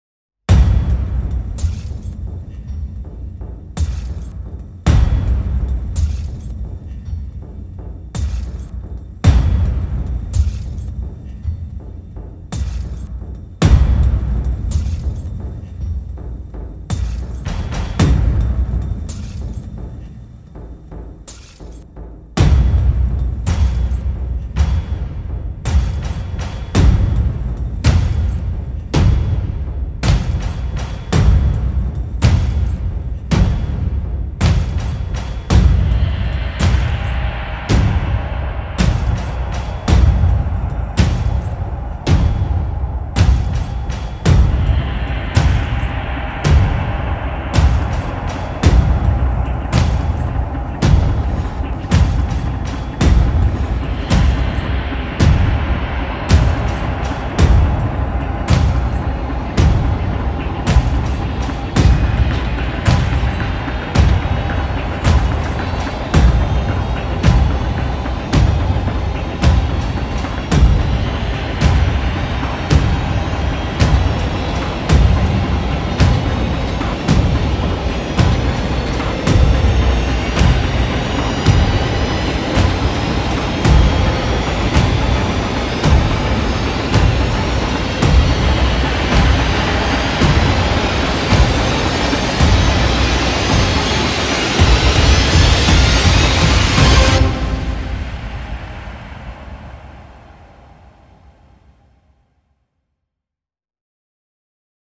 Largo [0-10] suspense - percussions - - -